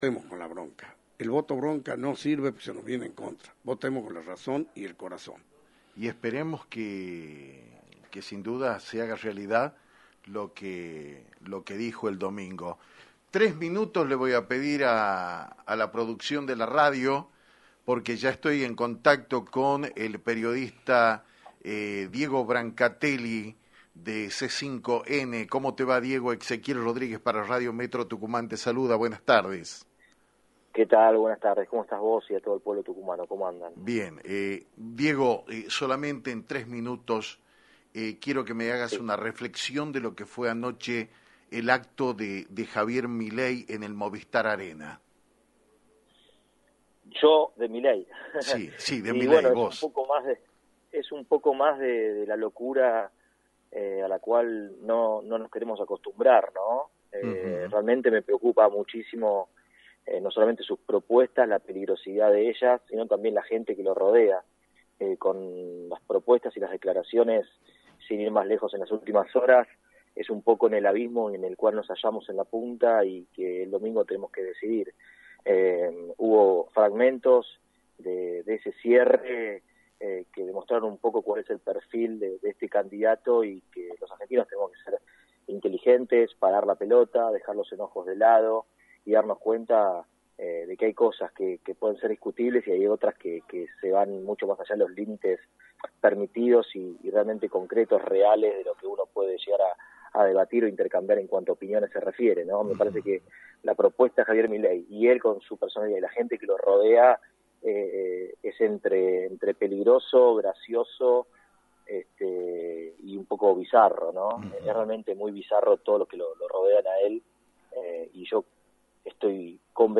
En dialogo exclusivo con Actualidad en Metro